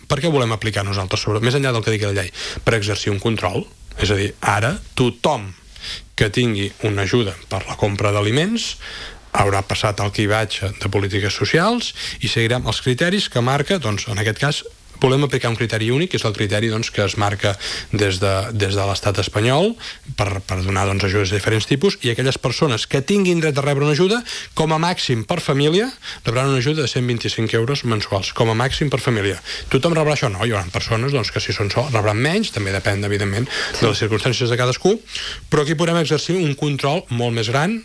N’ha parlat en una entrevista al matinal de Ràdio Calella Televisió, on ha detallat que el nou sistema s’implantarà a partir del 2026.